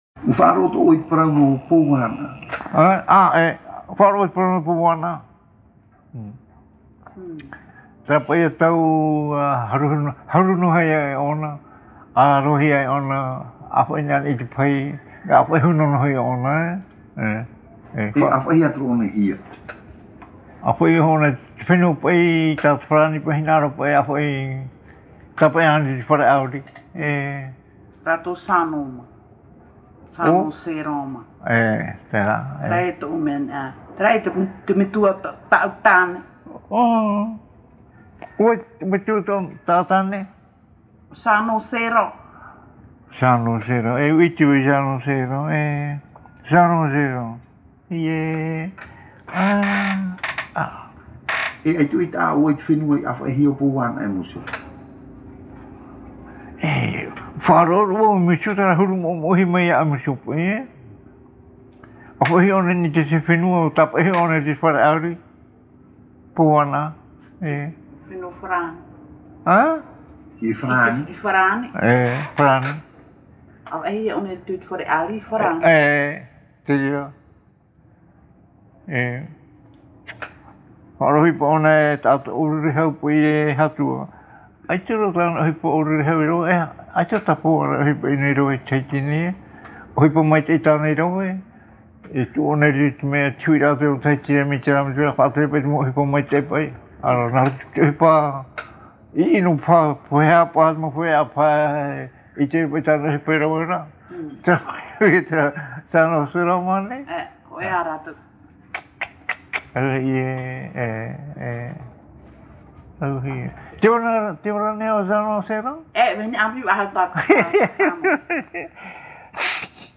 Interview réalisée à Tīpaerui sur l’île de Tahiti.
Papa mātāmua / Support original : cassette audio